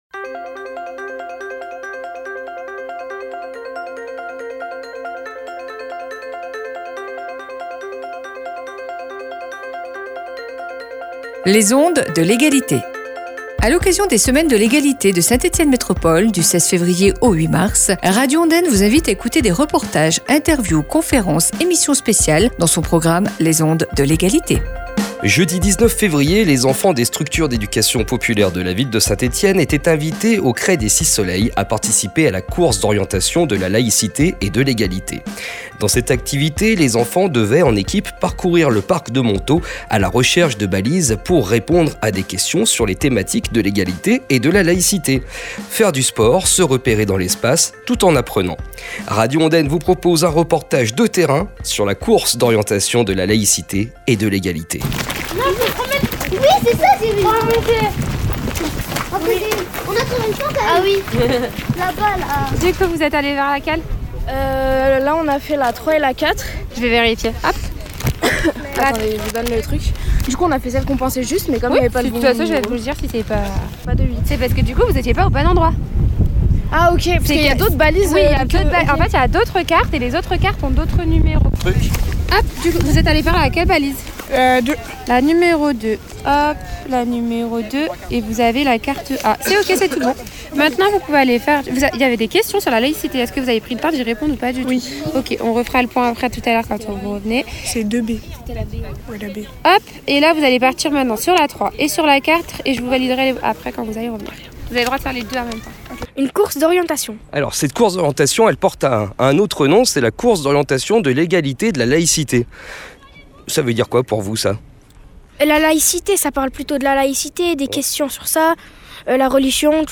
Le jeudi 19 Février, les enfants des structures d’éducation populaire de la ville de Saint-Etienne étaient invités, aux Crêts des Six Soleil, à participer à la Course d’Orientation de la l’Égalité et de la Laïcité. Par équipes, les participants devaient arpenter le Parc de Montaud à la recherche de balises pour répondre à une série de questions sur le thèmes du jour. Radio Ondaine vous invite à entendre un reportage sur cet évènement aujourd’hui à 8h15.